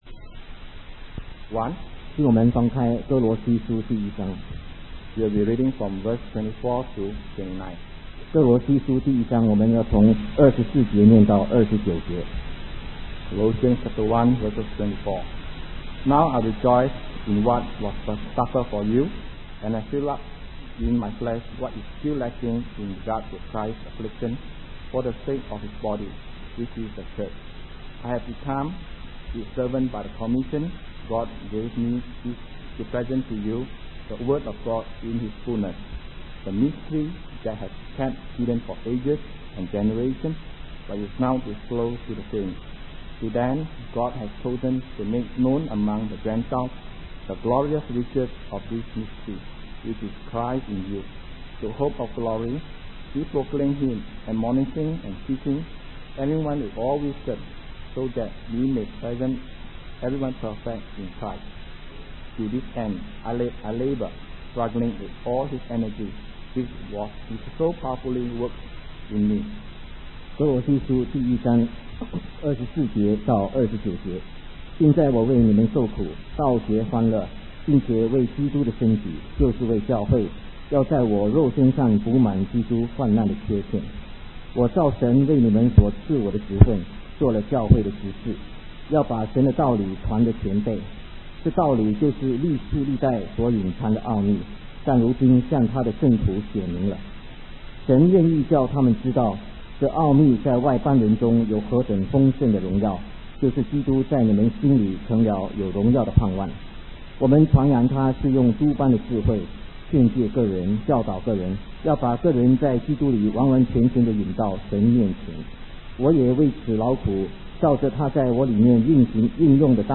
In this sermon, the speaker begins by reading from Colossians 1:24-29.